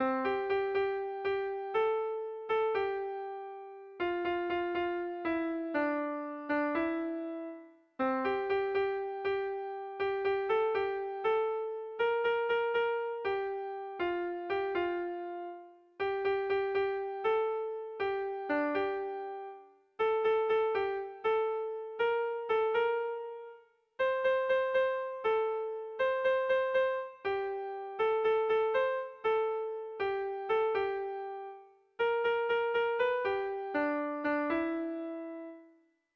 Sehaskakoa